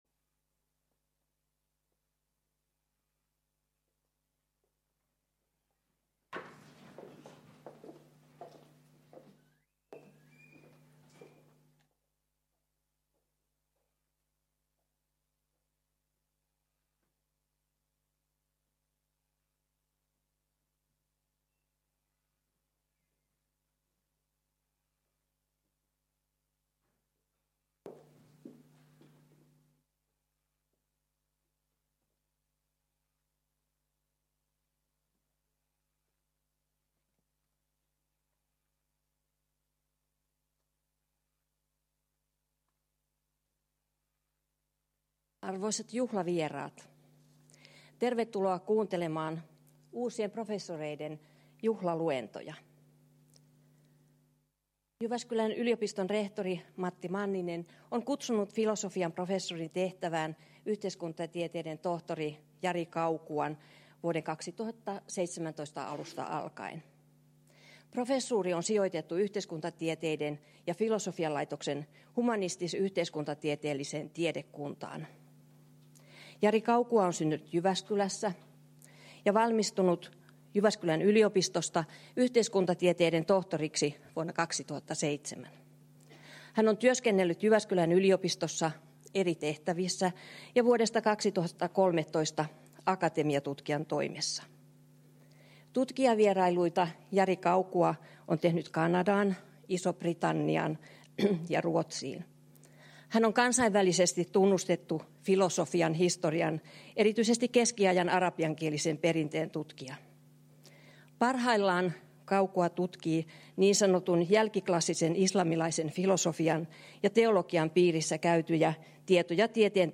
Tilaisuuden avaus, Martti Ahtisaari-sali